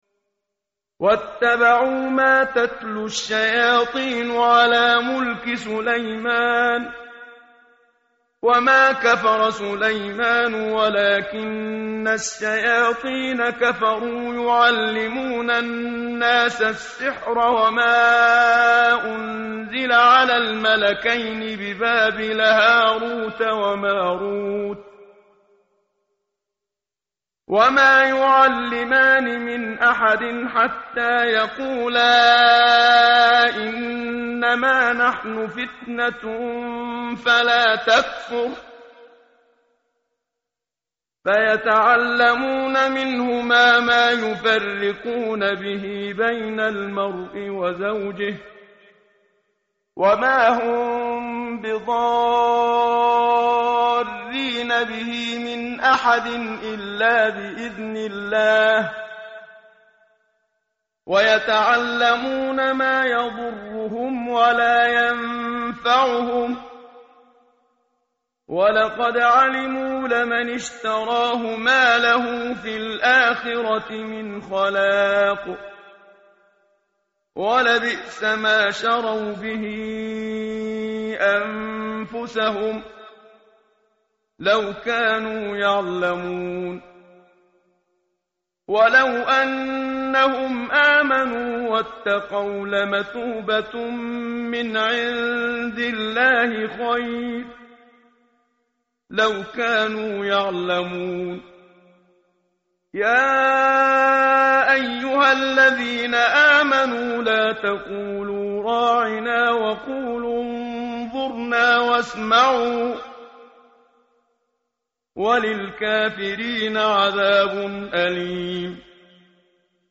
tartil_menshavi_page_016.mp3